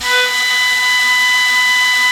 FLUTELIN12.wav